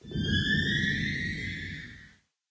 cave10.ogg